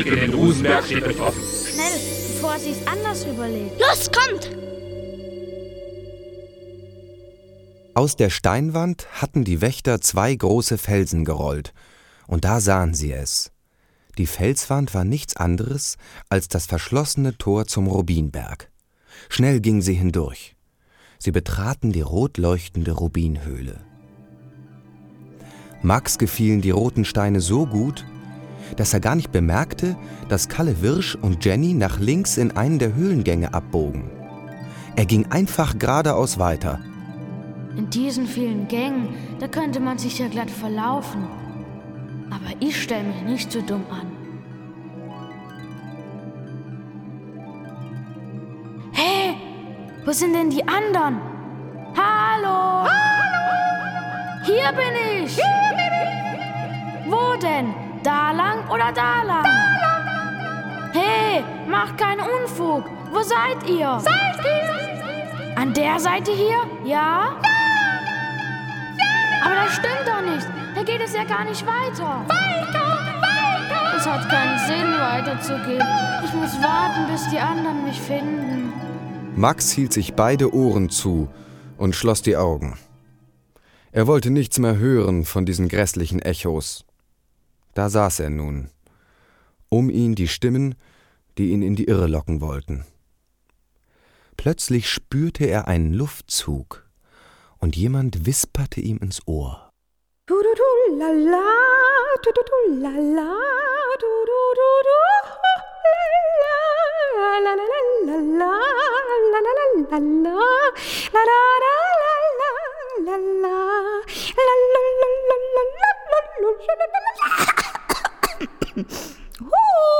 Schlagworte Abenteuer • Augsburger Puppenkiste • Erdmännchenreich • Erzfeinde • Hörbuch; Hörspiel für Kinder/Jugendliche • Machtkampf